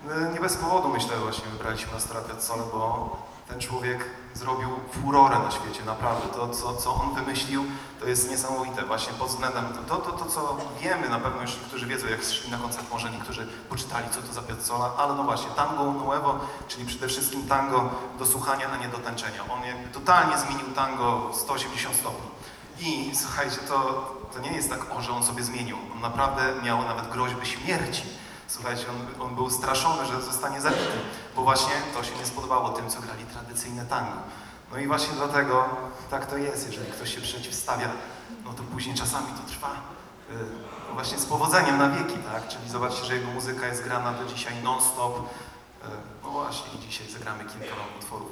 „Jesienne barwy muzyki” – nastrojowy piątkowy koncert w SOK-u